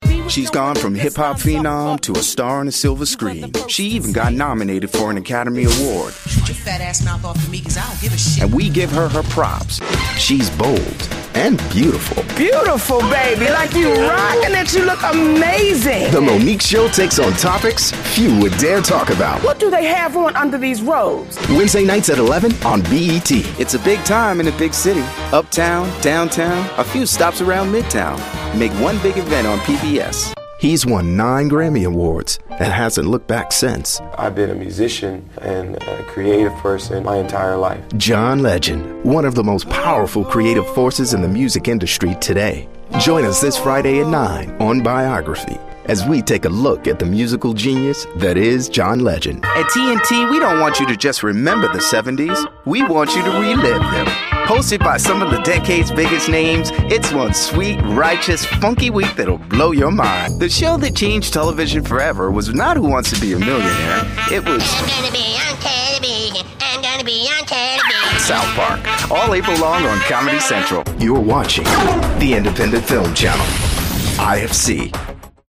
mid-atlantic
middle west
Sprechprobe: Industrie (Muttersprache):